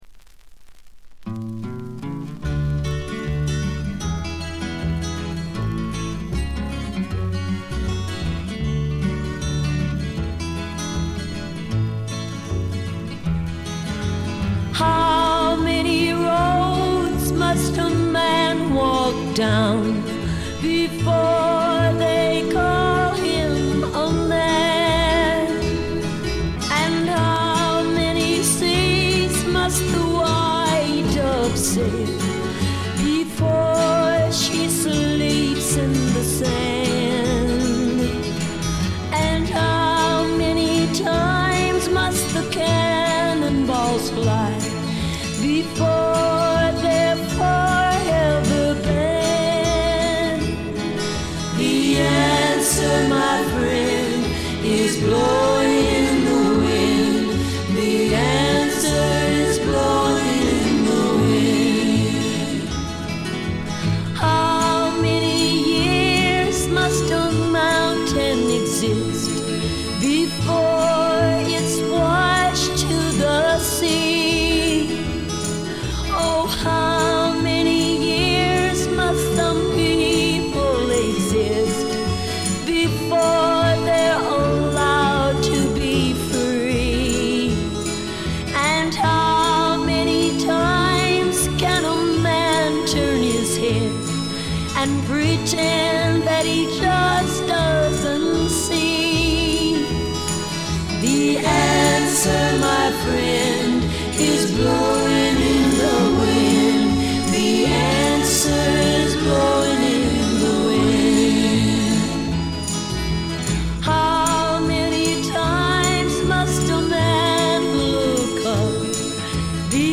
全体に細かなチリプチ、バックグラウンドノイズが出ていますが気になるのはこのB1ぐらい。
美しいフォーク・アルバムです。
最初期のモノラル盤。
試聴曲は現品からの取り込み音源です。